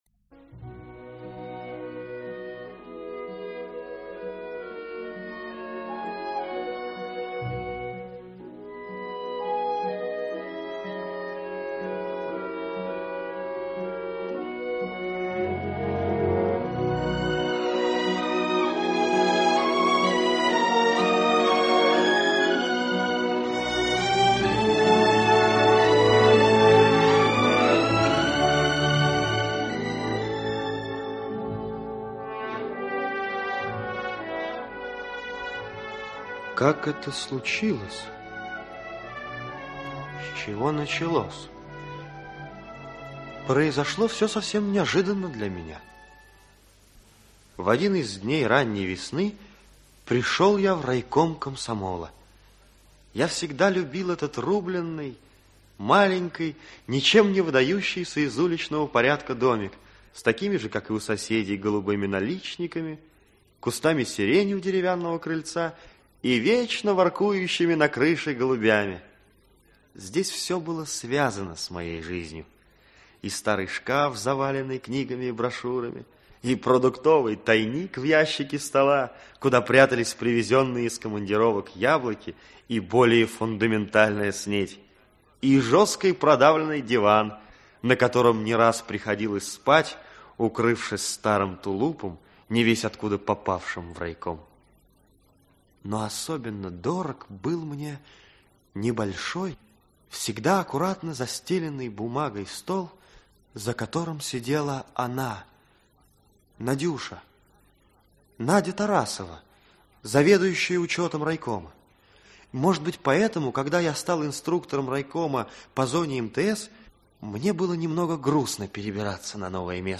Аудиокнига Трудная должность | Библиотека аудиокниг
Aудиокнига Трудная должность Автор Сергей Богомазов Читает аудиокнигу Актерский коллектив.